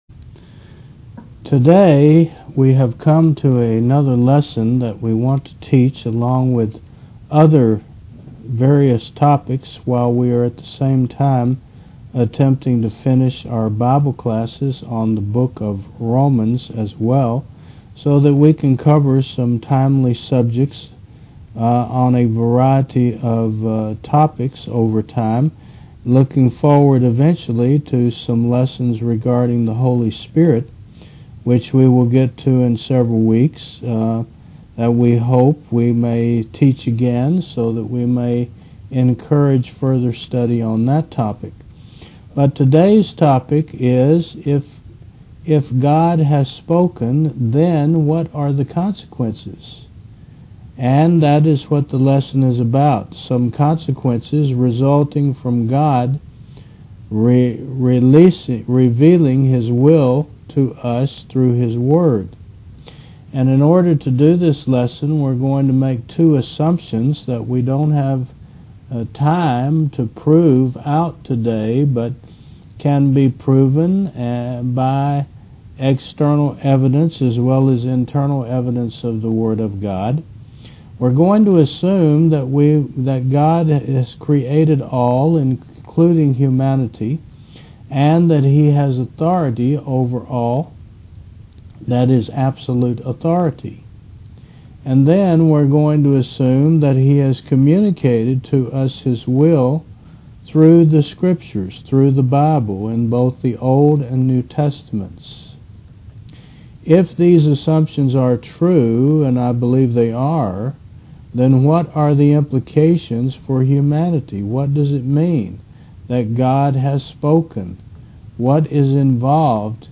Faith Service Type: Wed. 10 AM If God has communicated His Word to us in the bible